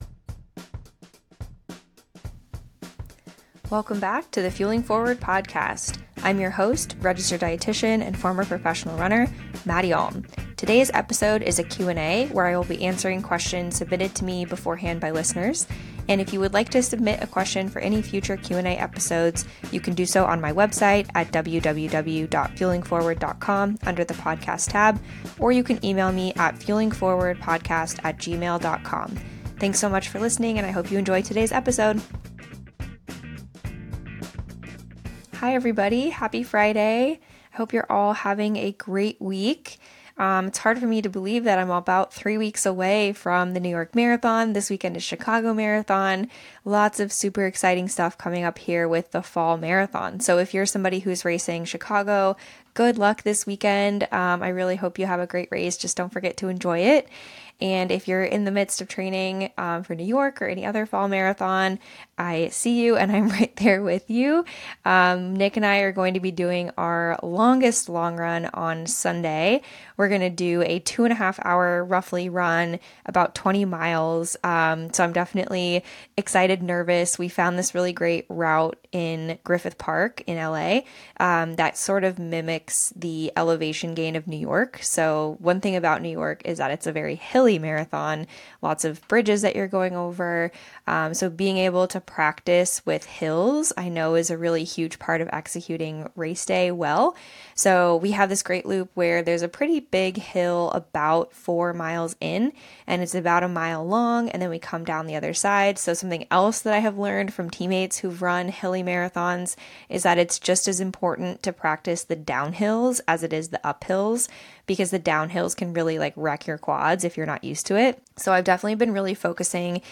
1 Q&A 2: Age & Performance, Achilles Injury Expectations, MRI Alternatives, Tendon Health & Stress Shielding 35:15